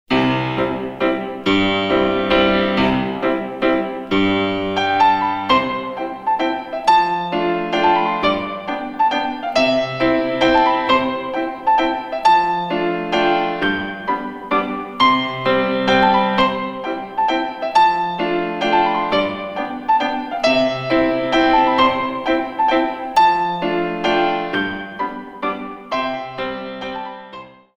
In 3
128 Counts